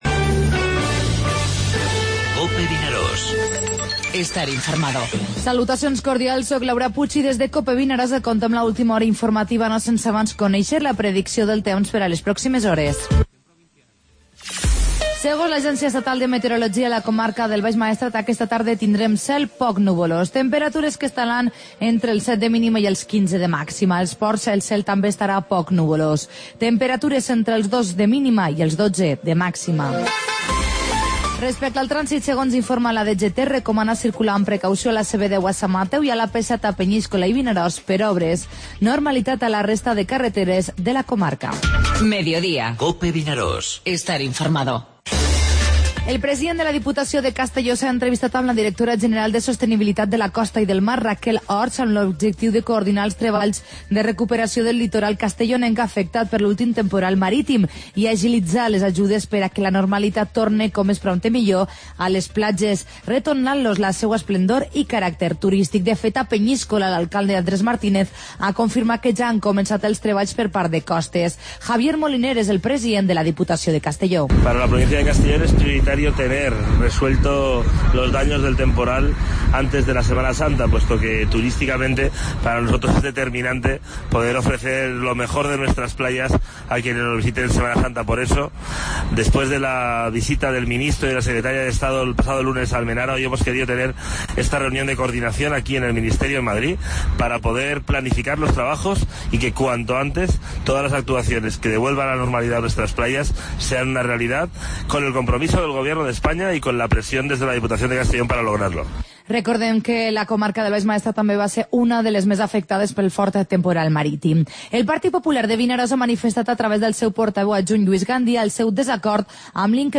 Informativo Mediodía COPE al Maestrat (dimarts 31 de gener)